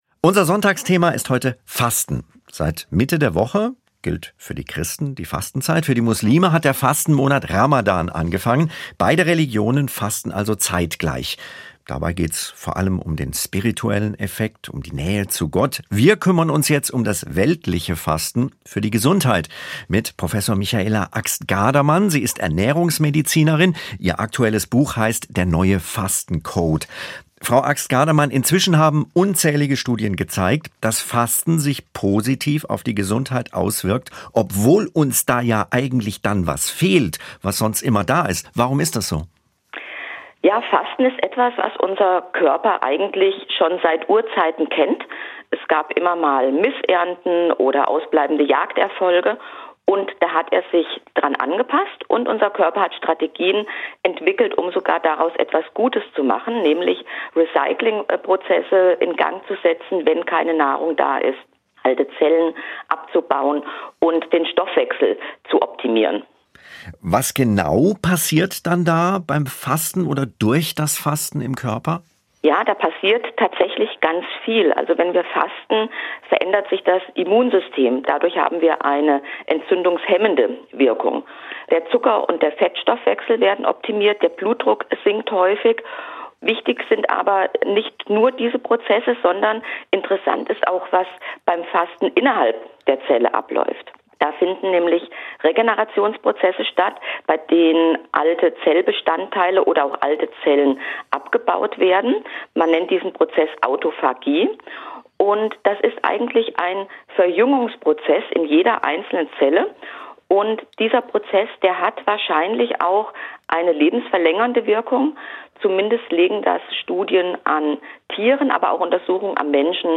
Wirkung des Fastens auf die Gesundheit - Gespräch